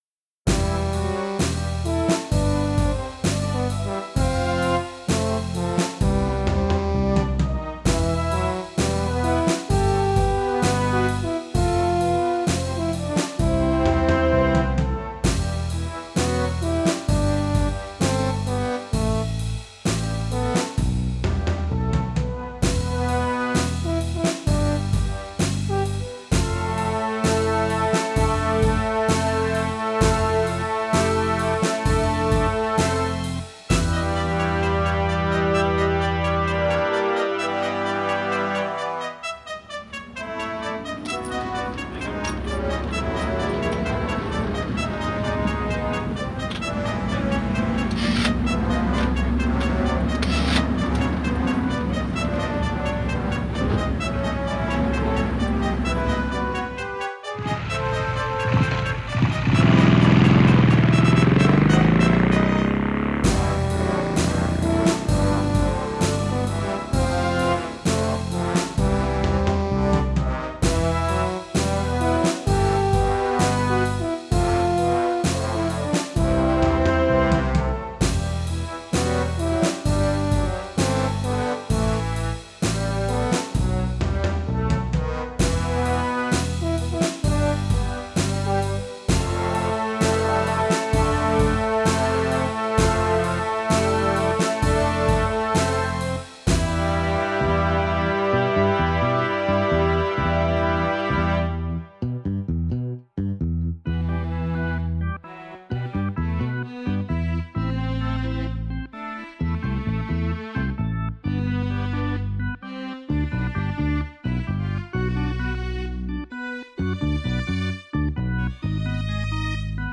[Backing Track]